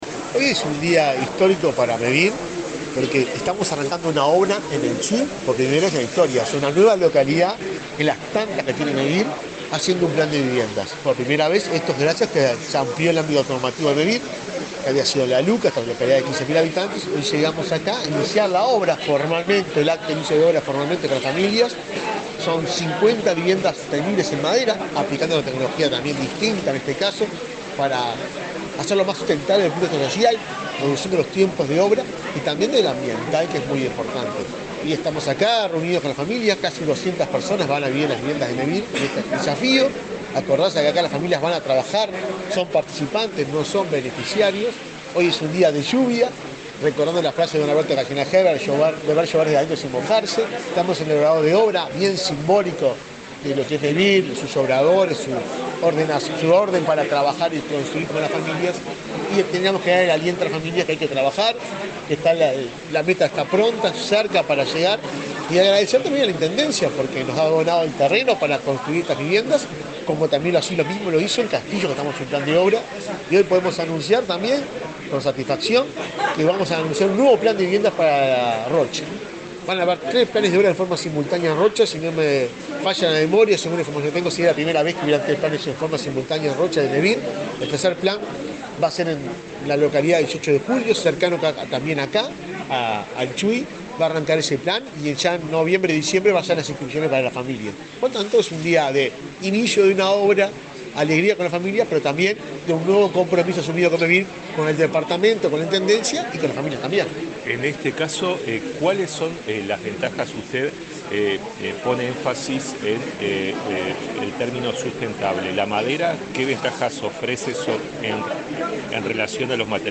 Declaraciones del presidente de Mevir, Juan Pablo Delgado
El presidente de Mevir, Juan Pablo Delgado, dialogó con la prensa antes de participar en el acto de lanzamiento de la construcción de 50 viviendas en